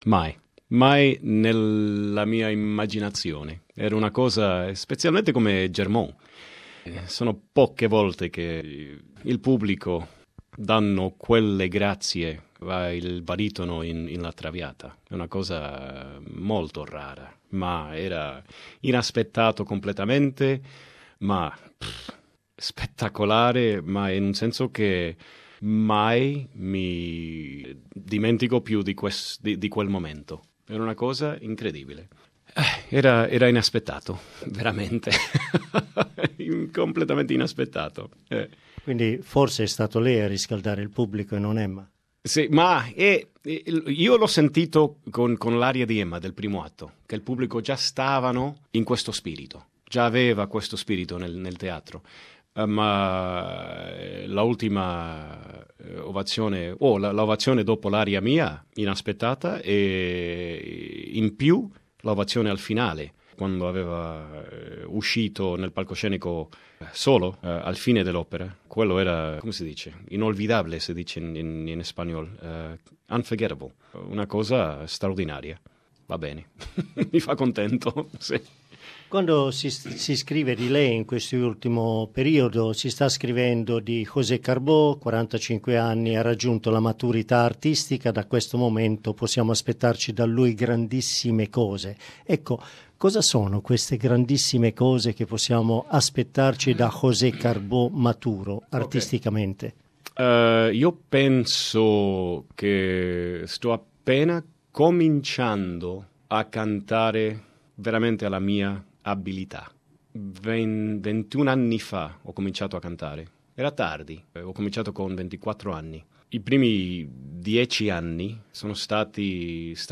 Australian baritone José Carbó's outstanding performance as Giorgio Germont in Verdi's La Traviata will be long remembered. In this interview Carbó reveals the unforgettable feeling on the Opera House stage when the public repeatedly erupted in spontaneous ovations.